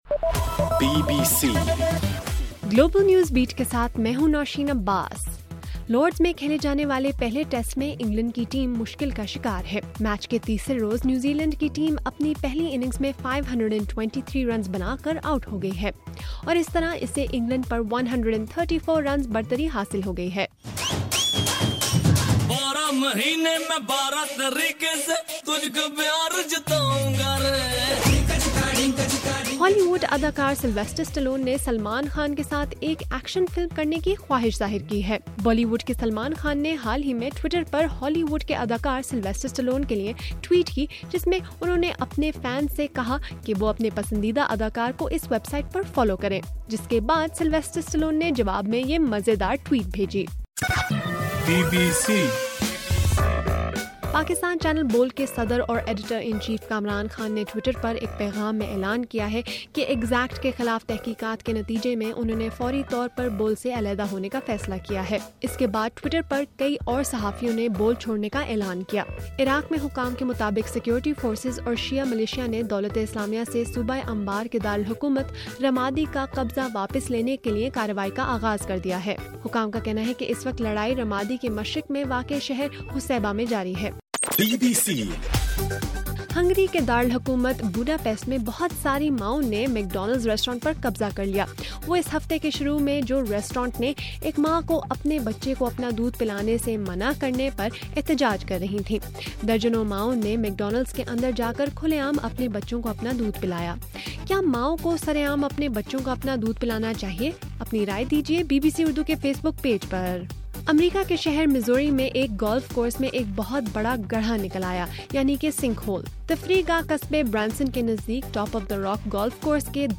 مئی 23: رات 12 بجے کا گلوبل نیوز بیٹ بُلیٹن